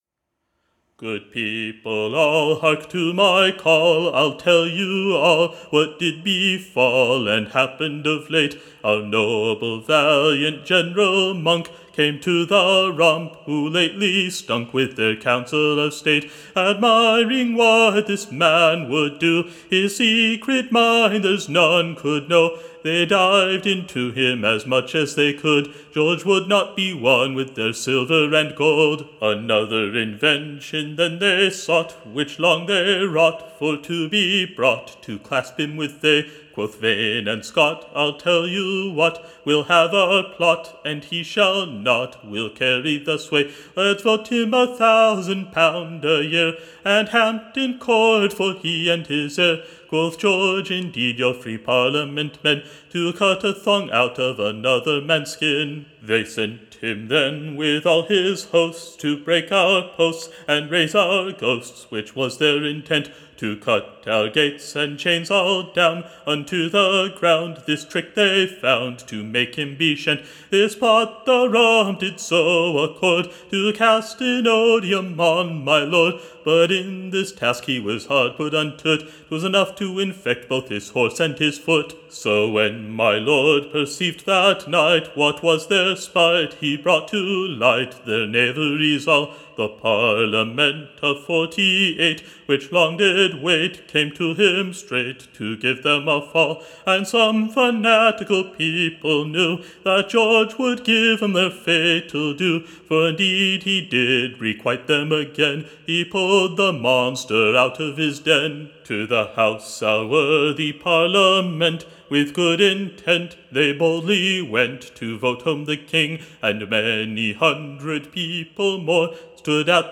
Recording Information Ballad Title Iter Boreale, the Second part, / RELATING / The Progress of the Lord General Monk, / Calling in the Secluded Members, their Voting King / CHARLS the Second home, his Joyfull reception at Dover.